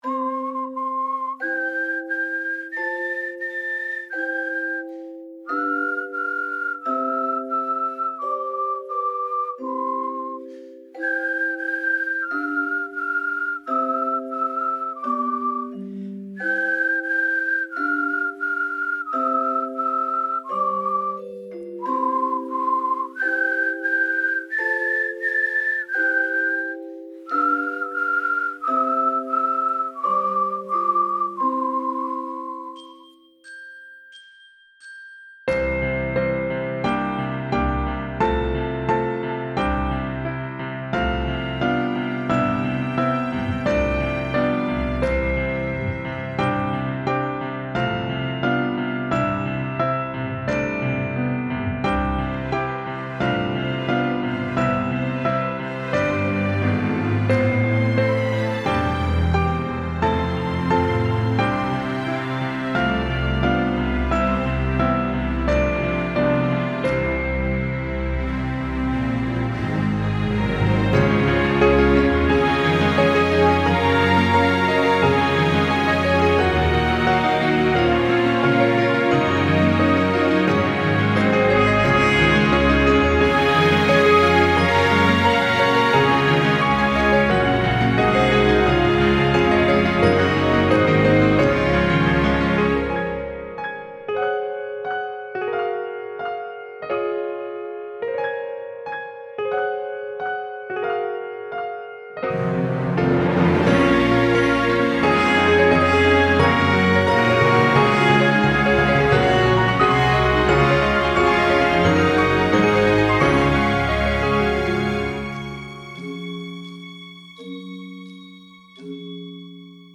Orchestra x Piano